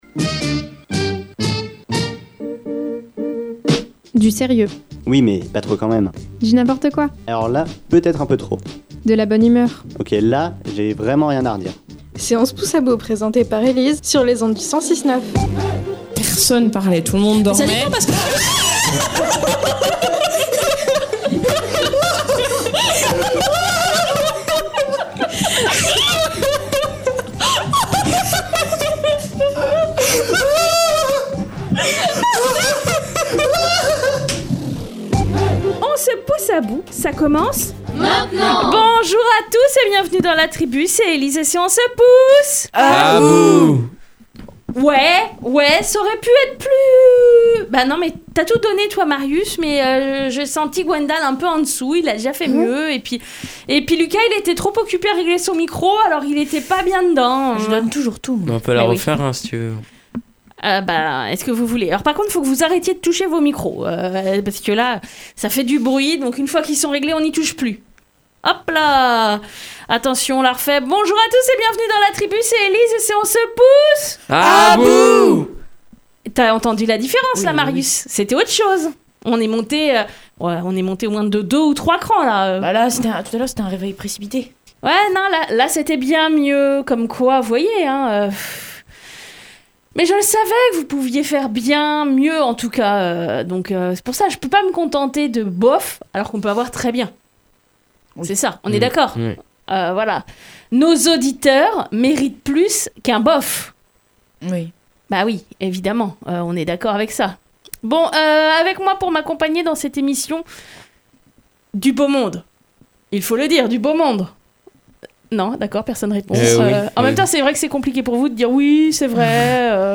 en direct de Tunisie !!!